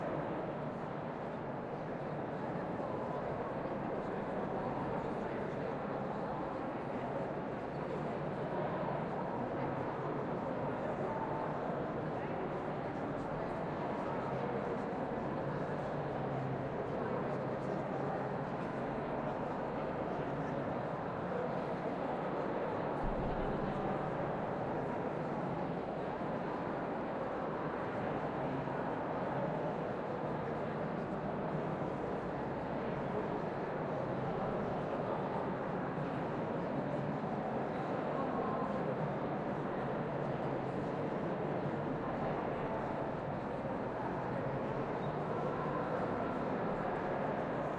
teenageCrowdMurmurLoop.ogg